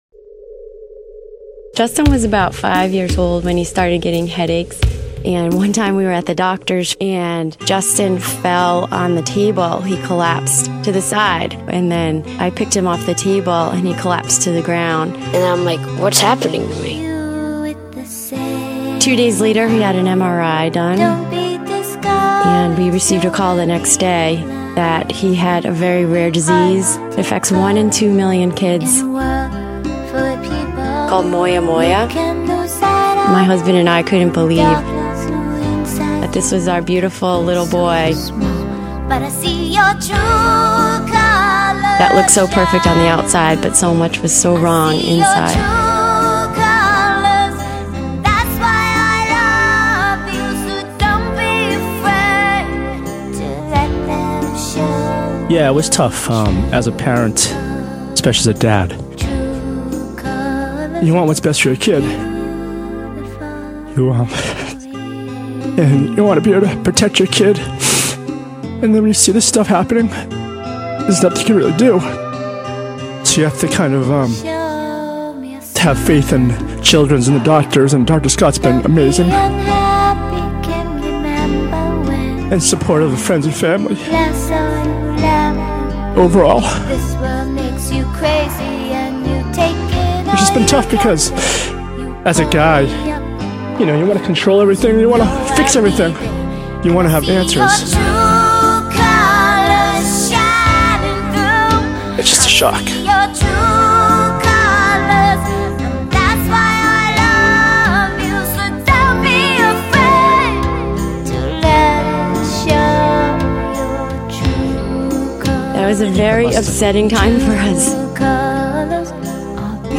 Mix 104.1 Cares for Kids Radiothon Story Song Interview